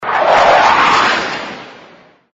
air_strike.mp3